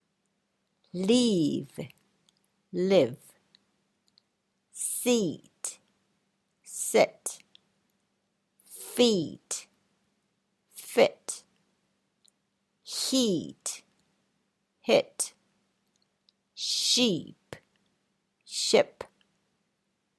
2-4-3. “Word pairs”　＝「ペアーの言葉」で発音してみましょう。
leave“　＝「去る」 – “live” ＝「生活する」
sheep“　＝「ひつじ」 – “ship“　＝「船」